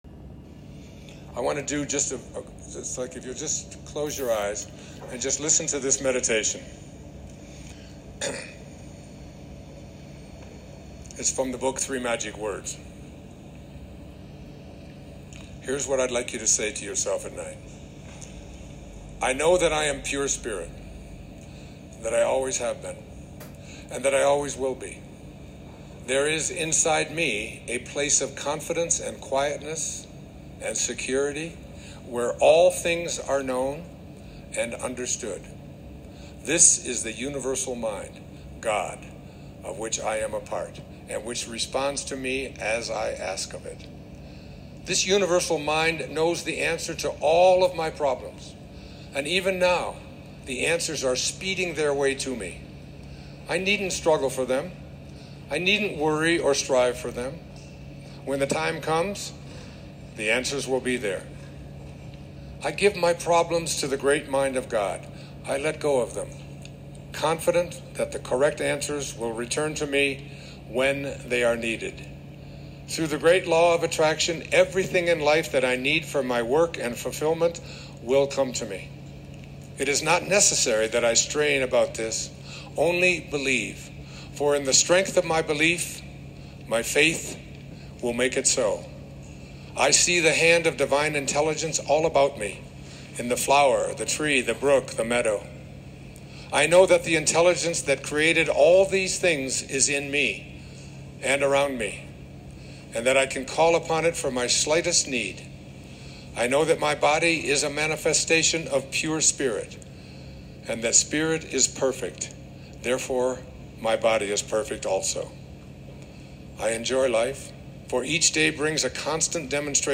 Ideally before bedtime, close your eyes and listen to this short meditation by Wayne Dyer from the book “Three magic words”.
wayne-dyer-meditation.m4a